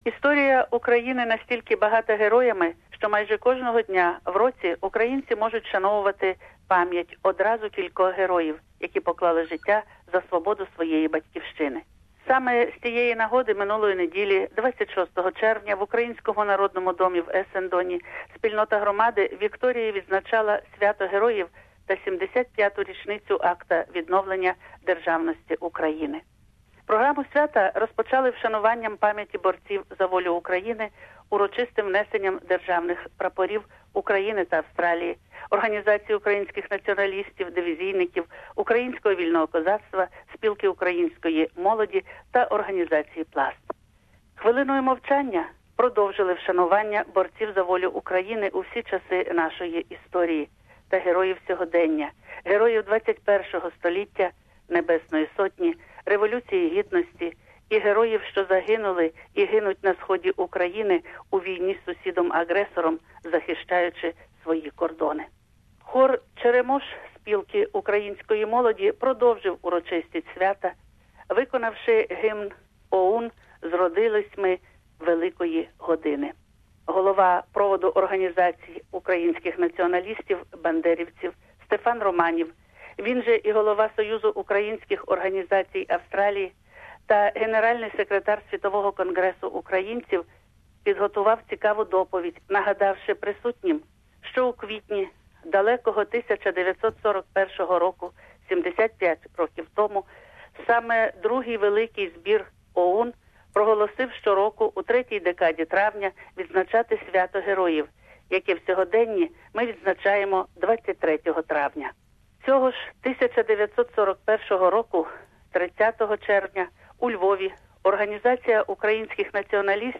Report.